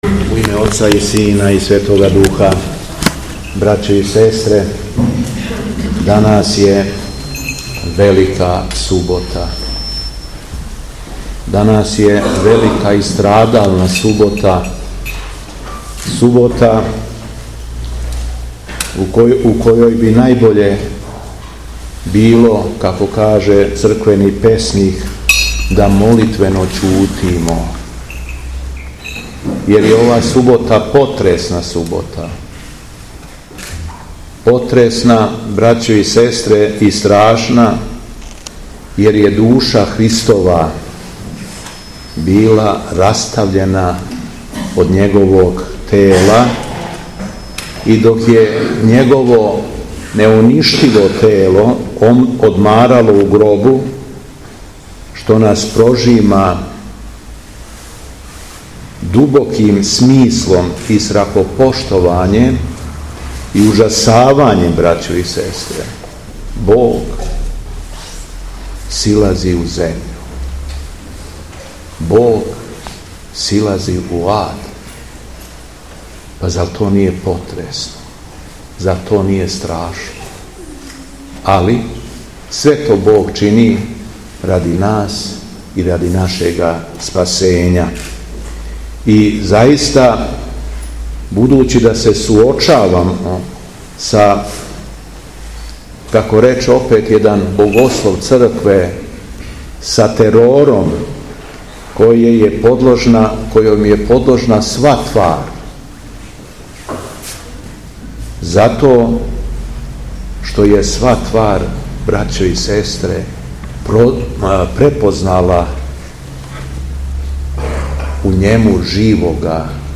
Беседа Његовог Преосвештенства Епископа шумадијског г. Јована
Након прочитаног јеванђелског зачала, Епископ се беседом обратио препуном храму, рекавши: